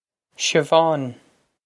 Siobhán Sheh-vahn
This is an approximate phonetic pronunciation of the phrase.